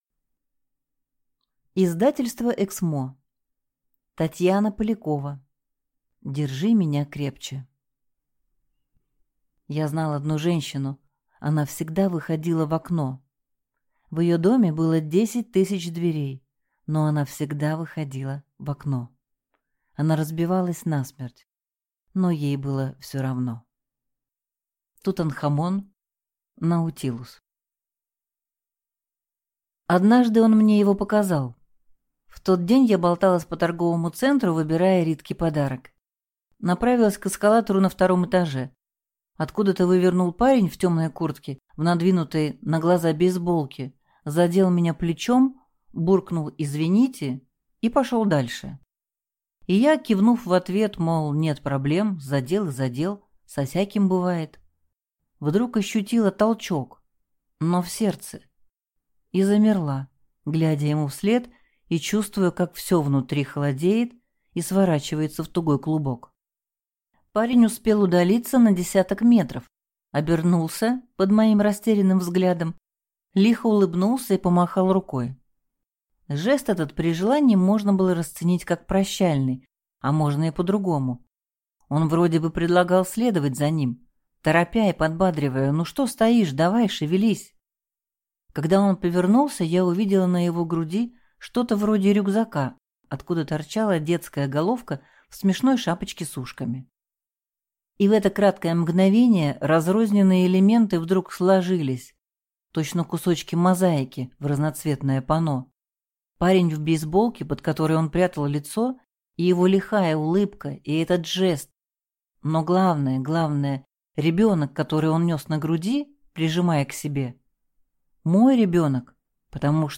Аудиокнига Держи меня крепче | Библиотека аудиокниг